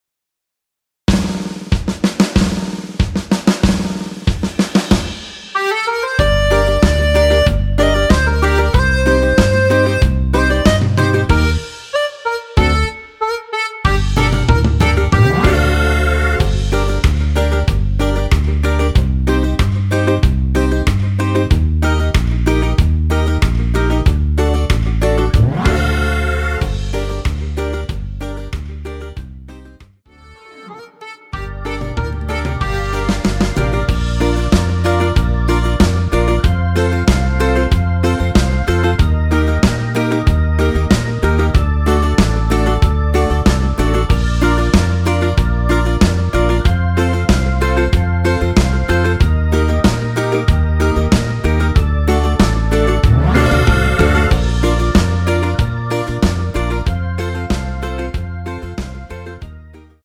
원키에서(+1)올린 MR입니다.
Gm
앞부분30초, 뒷부분30초씩 편집해서 올려 드리고 있습니다.